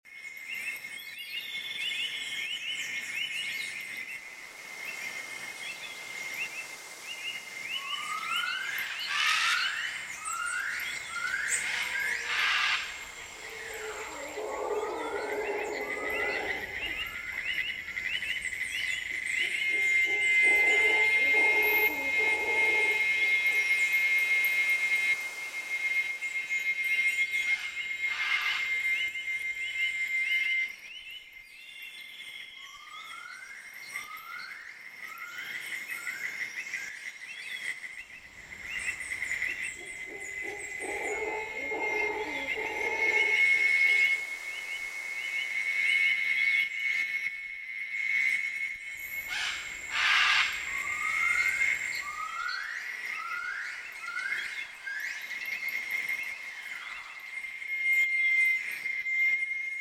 JungleAmbienceDay.ogg